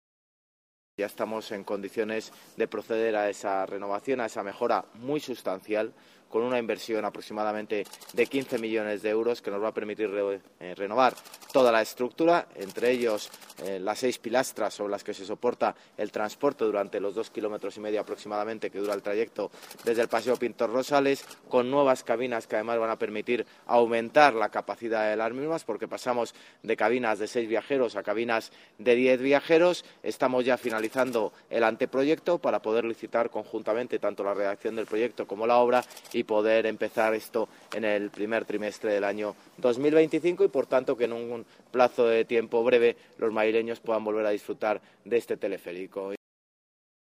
Nueva ventana:José Luis Martínez-Almeida, alcalde de Madrid